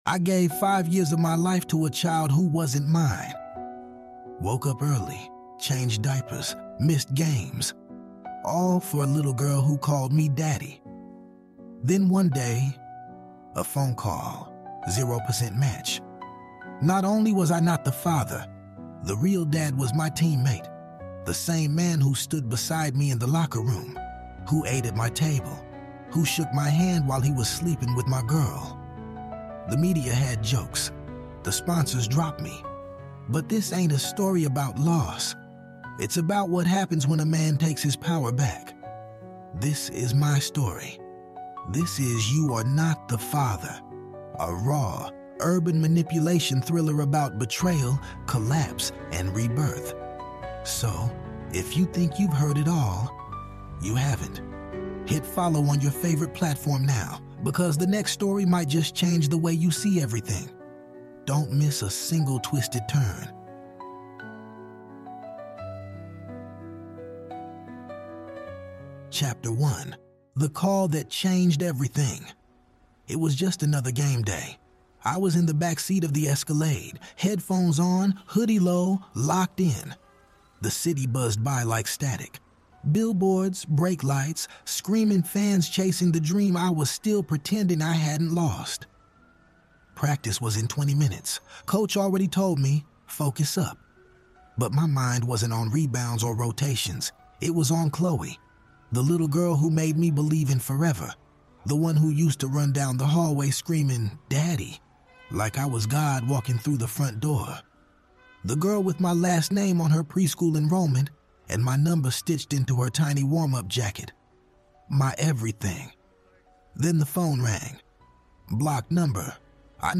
This audiobook isn't just about betrayal — it's about reclaiming emotional power through critical thinking, strategic detachment, and psycholo